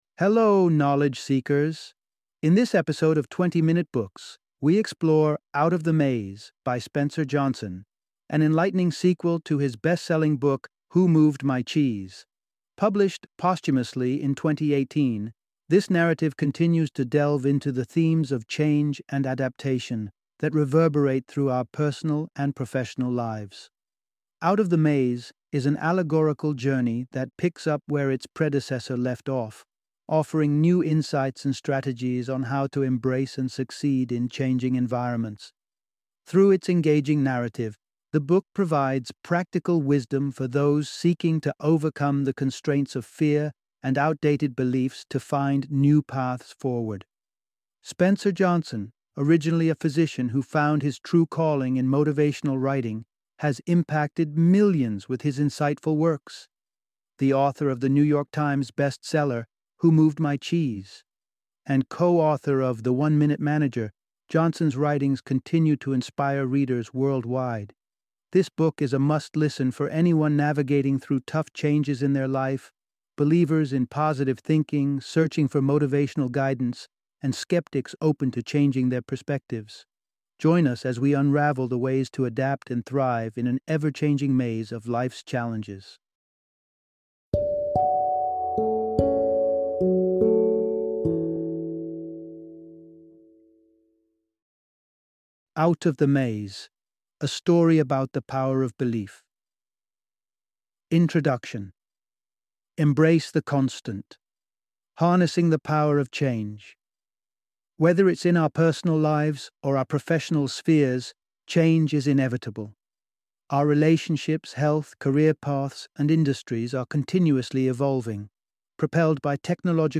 Out of the Maze - Audiobook Summary